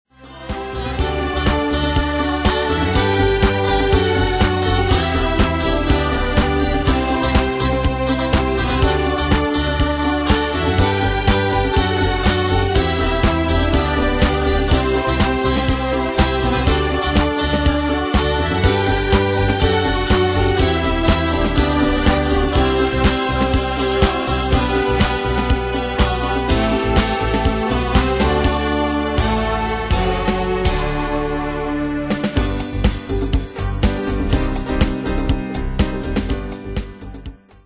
Demos Midi Audio
Danse/Dance: Dance Cat Id.